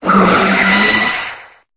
Cri de Zeraora dans Pokémon Épée et Bouclier.